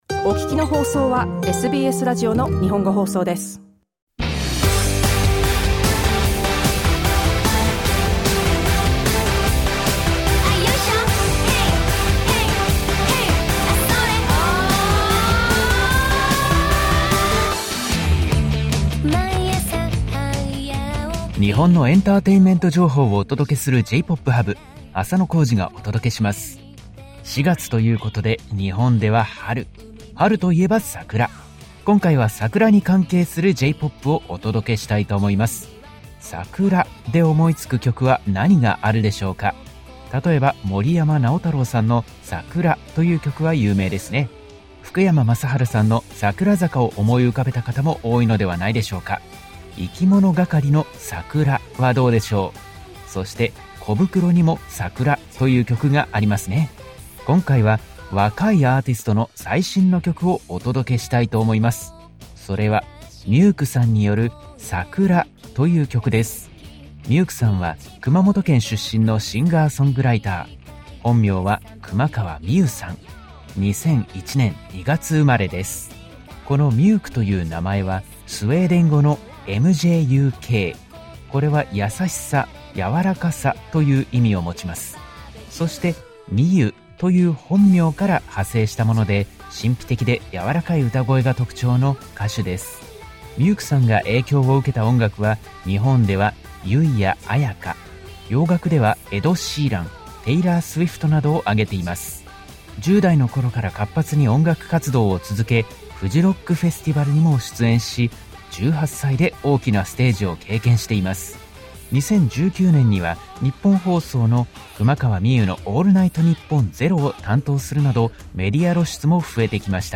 SBS Japanese's music segment J-Pop Hub is broadcast on Thursdays.